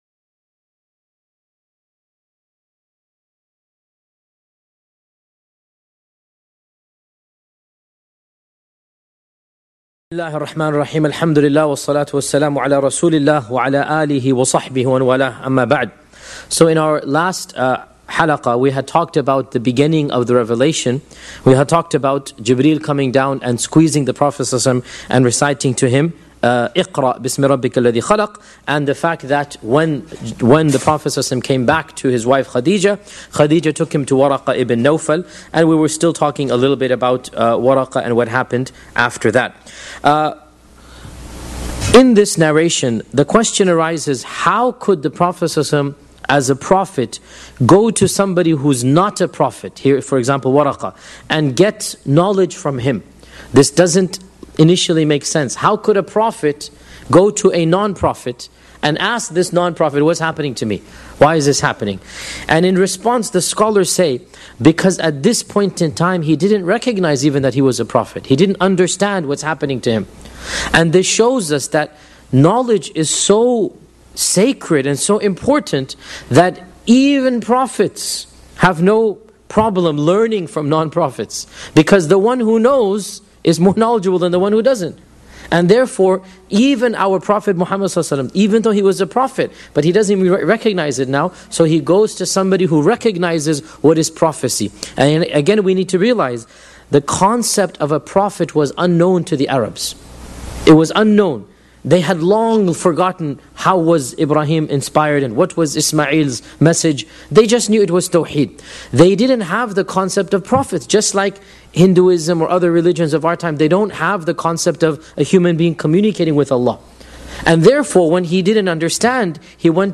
Shaykh Yasir Qadhi gives a detailed analysis of the life of Prophet Muhammed (peace be upon him) from the original sources.
This Seerah lecture covers a crucial chapter in early Islamic history — the three years of private da’wah during which Prophet Muhammad (peace be upon him) quietly invited people to Islam without making any public announcements.